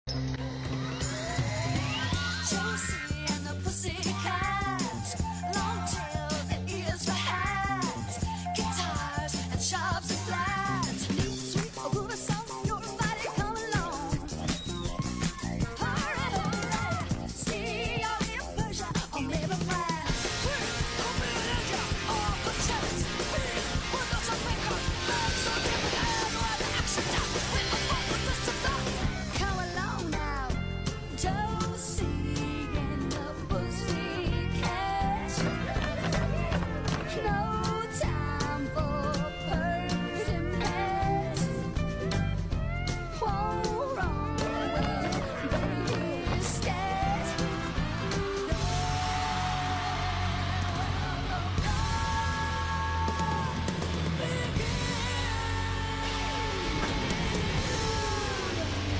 showcasing the evolution and different genres of music